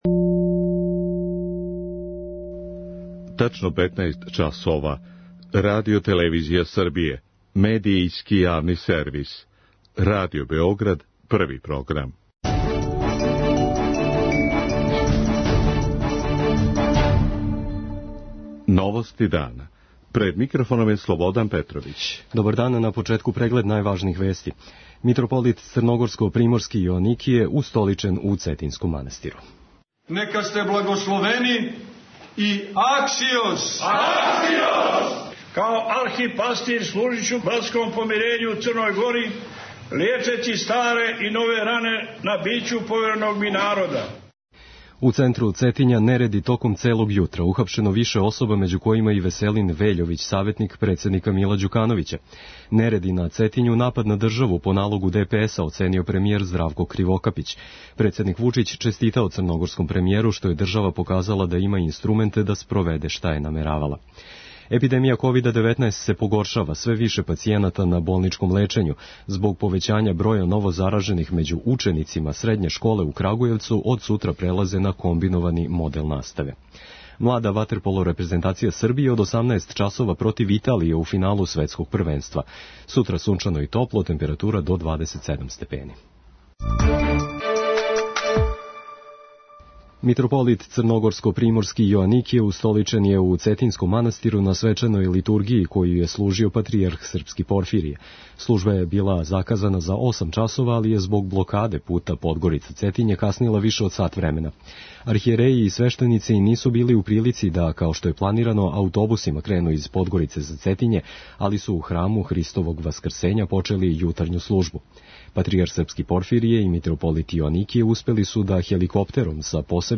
Због блокаде прилаза Цетињу, они су хеликоптером стигли до манастира и вратили се у Подгорицу. преузми : 5.90 MB Новости дана Autor: Радио Београд 1 “Новости дана”, централна информативна емисија Првог програма Радио Београда емитује се од јесени 1958. године.